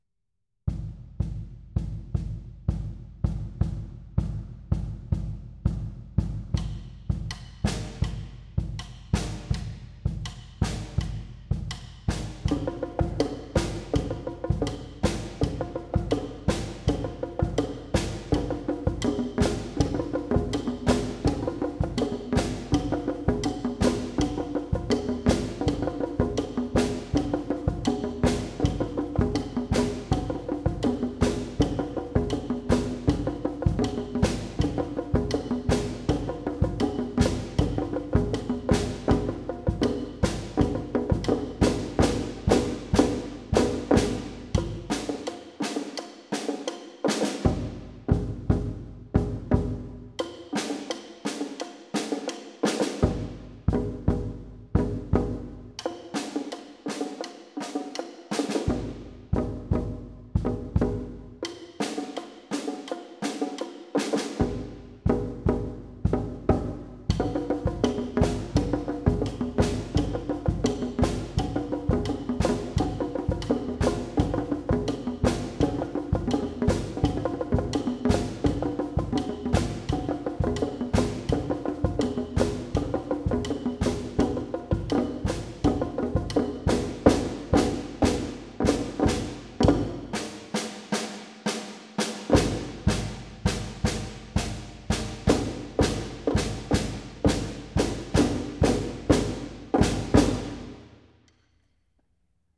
Die Musik wurde am 27. Mai 2023 Live von den Ensembles der Musikschule Oberägeri eingespielt.